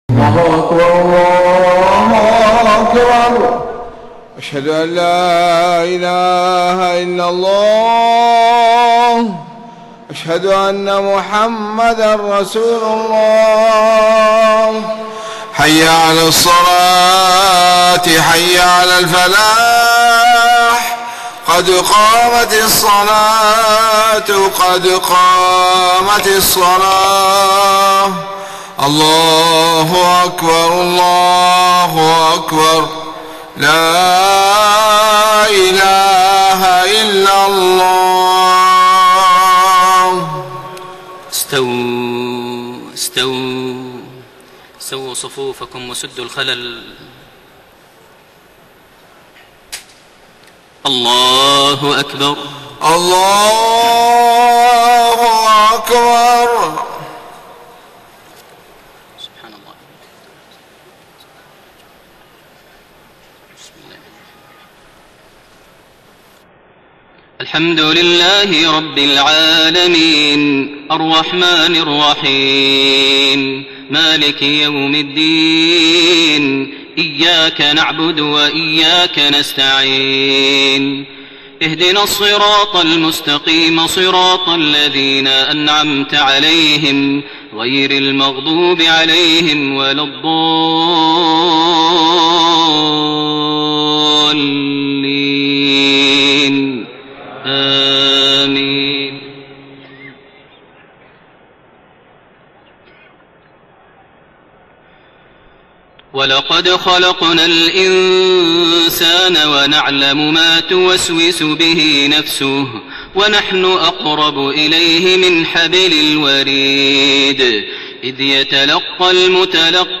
صلاة المغرب 19 ذو الحجة 1432هـ من سورة ق 16-35 > 1432 هـ > الفروض - تلاوات ماهر المعيقلي